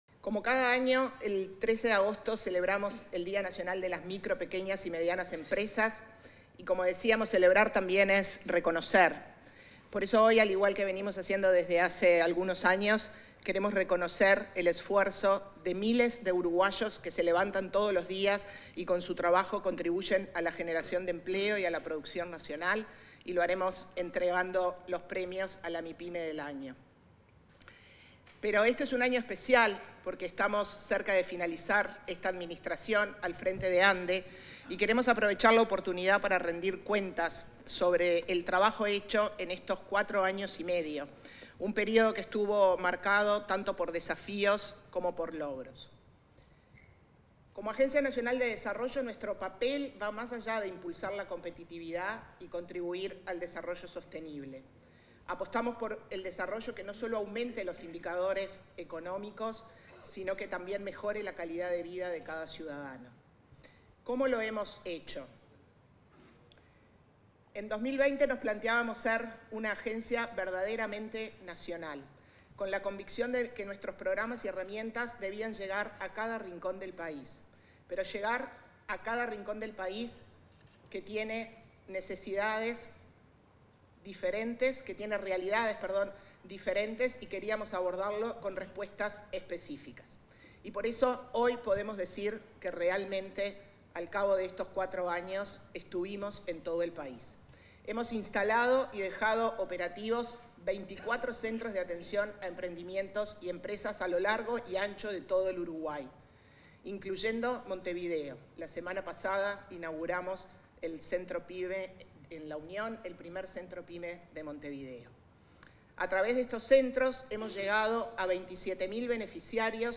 Palabras de la presidenta de ANDE, Carmen Sánchez
Palabras de la presidenta de ANDE, Carmen Sánchez 13/08/2024 Compartir Facebook X Copiar enlace WhatsApp LinkedIn Al conmemorarse el Día Nacional de las Mipymes, este 13 de agosto, se expresó la presidenta de la Agencia Nacional de Desarrollo (ANDE), Carmen Sánchez.